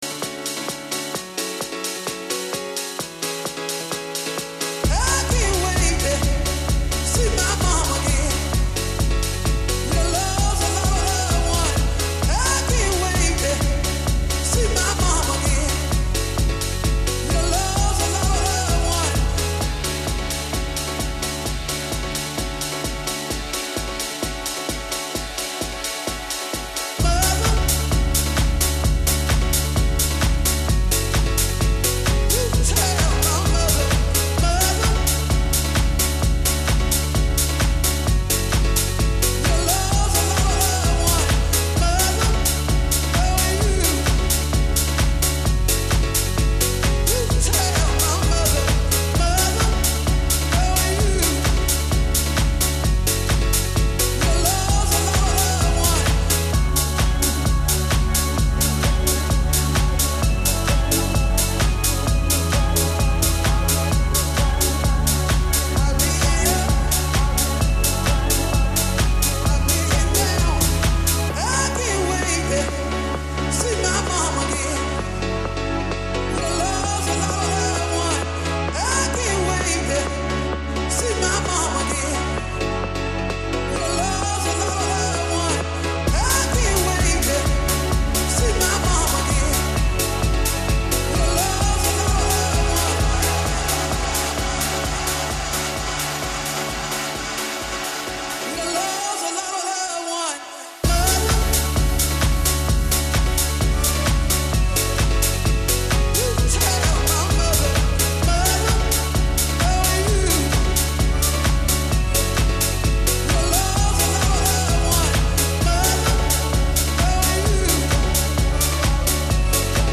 A stunning sunny spring evening in Whaingaroa Raglan - tunes to help you drive, clean, dance, keep your endorphins UP at the start of the work week :)